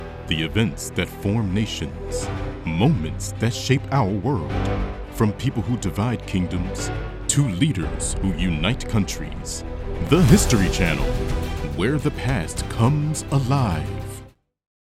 TV Promo Samples
Uplifting
TV-Promo-Demo_The-History-Channel.mp3